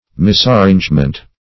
Misarrangement \Mis`ar*range"ment\, n. Wrong arrangement.